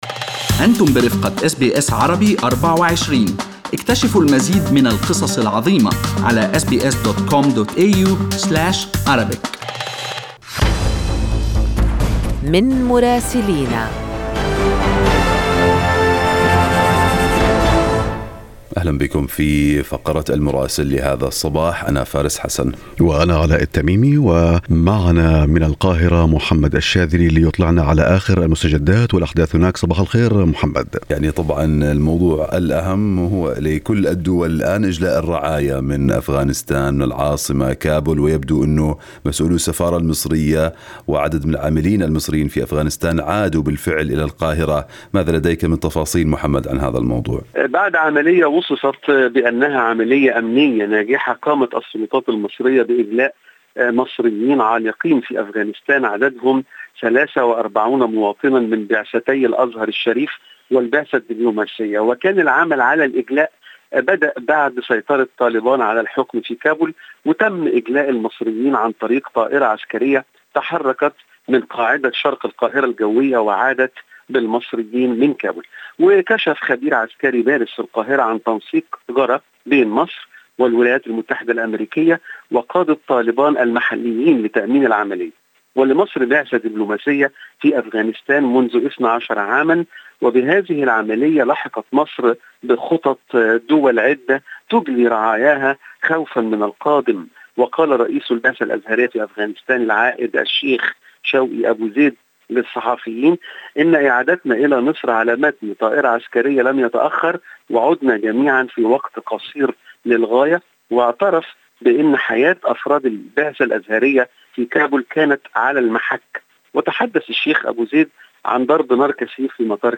من مراسلينا: أخبار مصر في أسبوع 25/8/2021